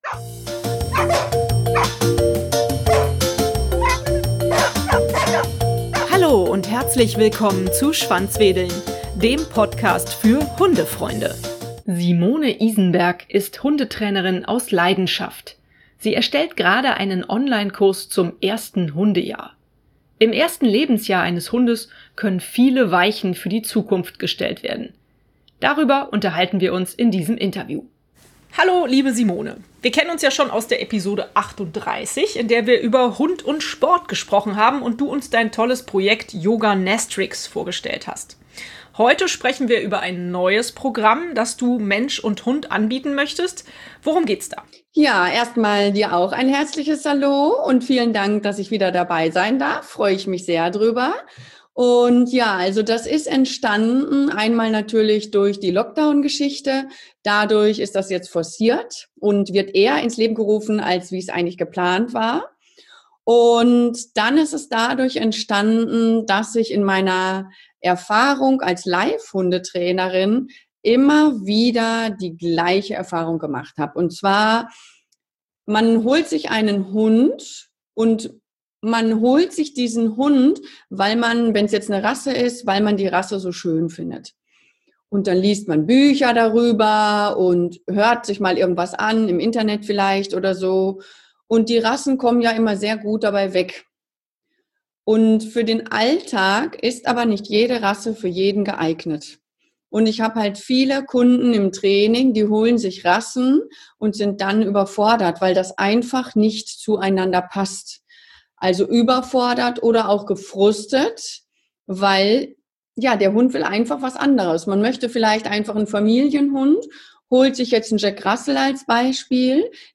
Darüber und über viele andere spannende Themen unterhalten wir uns in diesem Interview.